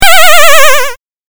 8bitアクション | 無料 BGM・効果音のフリー音源素材 | Springin’ Sound Stock
8bit下降5.mp3